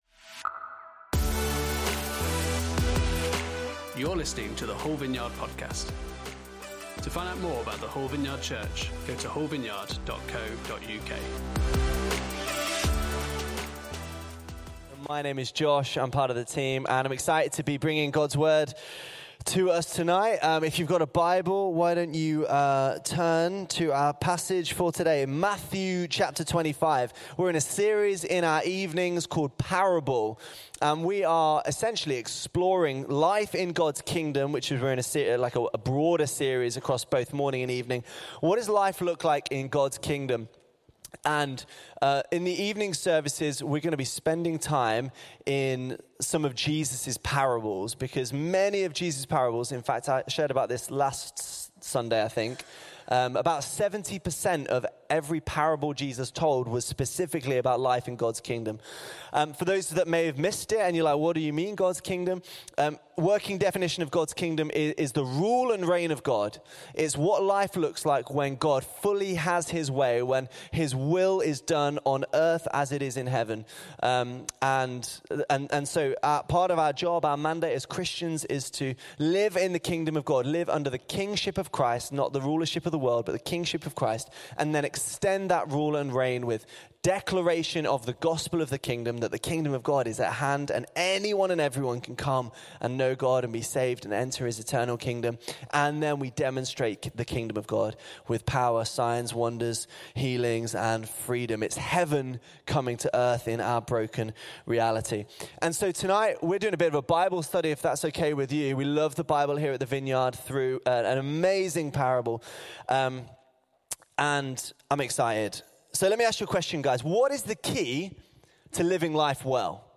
Parable Service Type: Sunday Service On Sunday evening